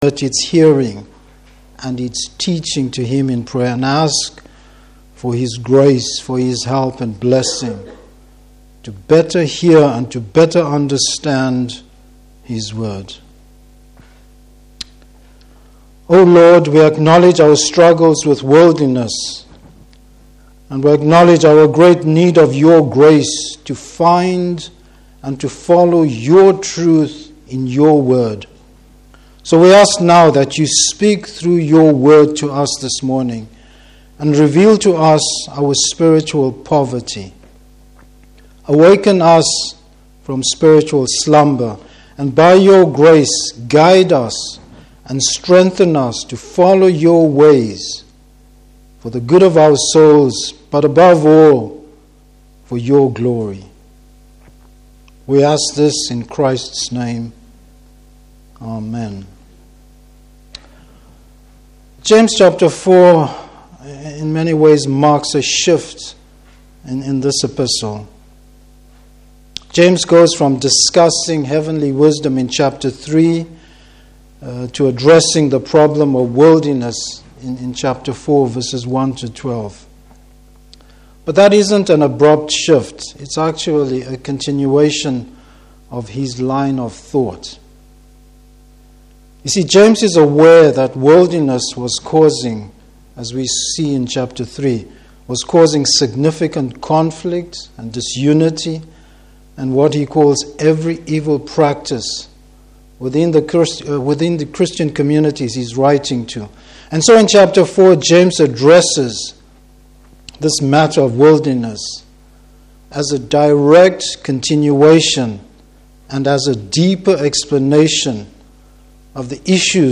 Service Type: Morning Service How God’s grace can tame our natural instincts.